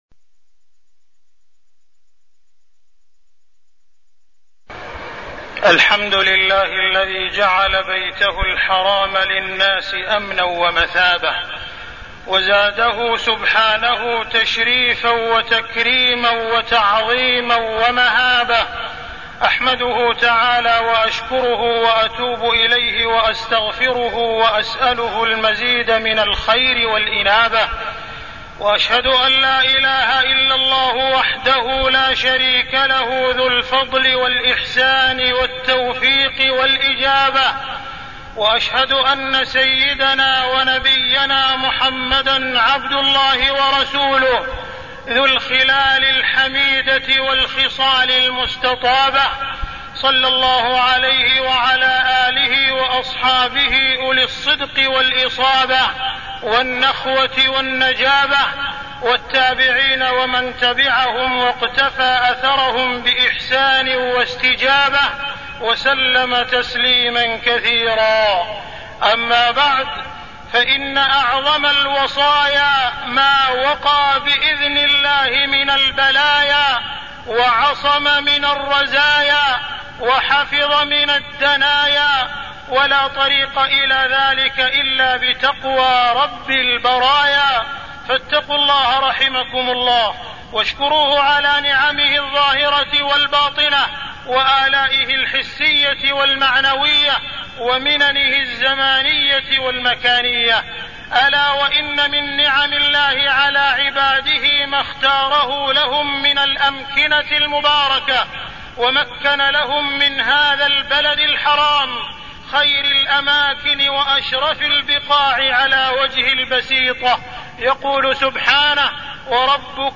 تاريخ النشر ٢٤ ذو القعدة ١٤١٩ هـ المكان: المسجد الحرام الشيخ: معالي الشيخ أ.د. عبدالرحمن بن عبدالعزيز السديس معالي الشيخ أ.د. عبدالرحمن بن عبدالعزيز السديس الحج إلى بيت الله الحرام